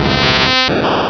Cri de Chrysacier dans Pokémon Rubis et Saphir.